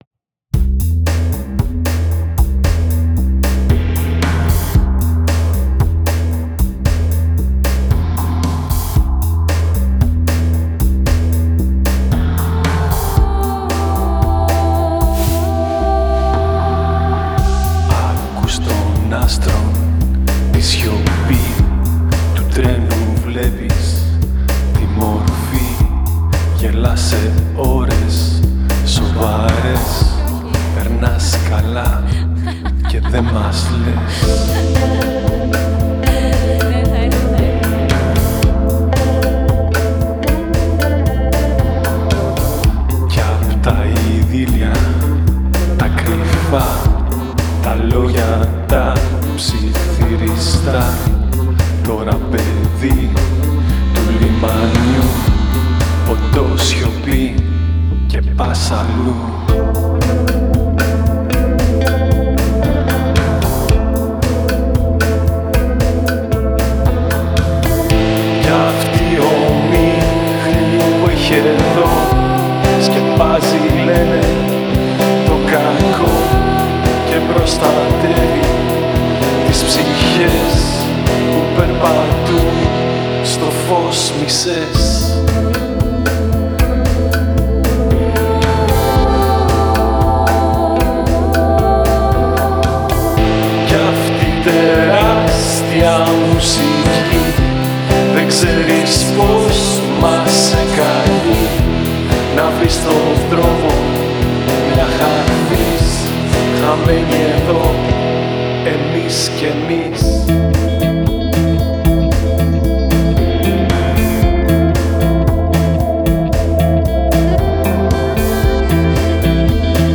κιθάρα, μπάσο, πλήκτρα
Κρουστά
Σαξόφωνο
τύμπανα
φλάουτο